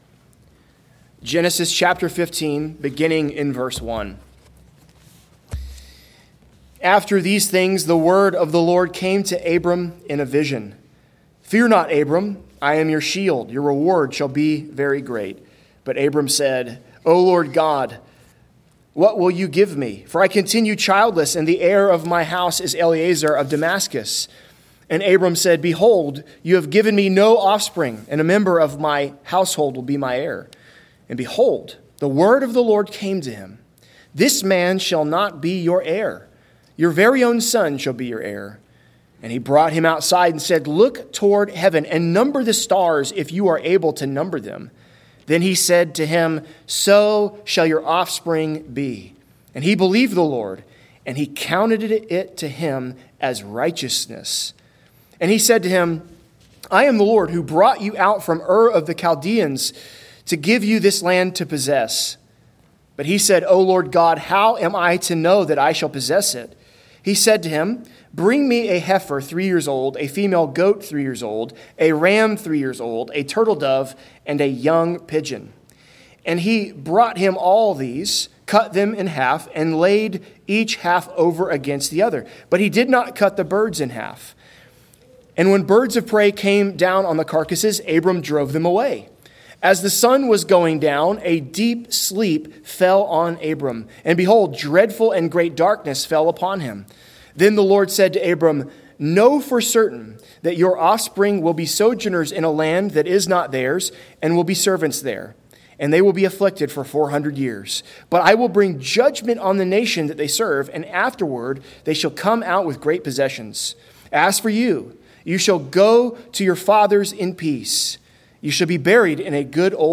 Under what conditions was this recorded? Galatians 2.15-16 Service Type: Sunday Worship Big Idea